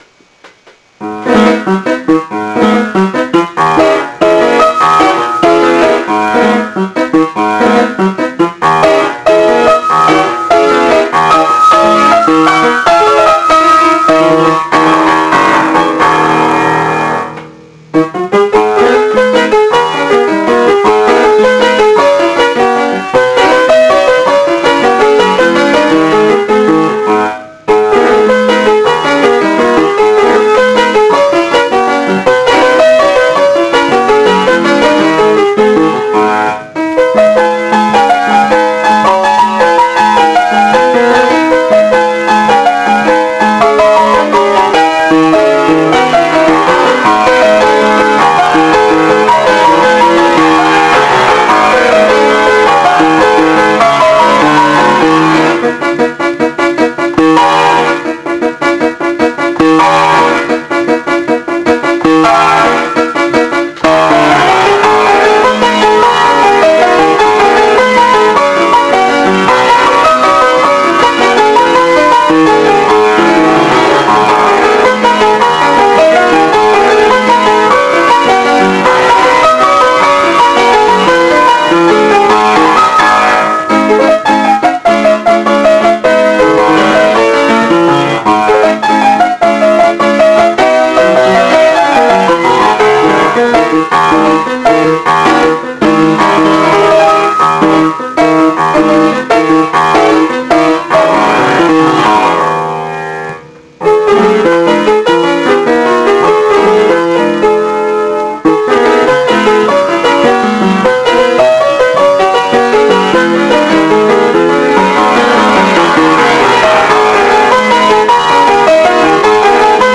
(puis c'est sympa, en rapide, non ?...)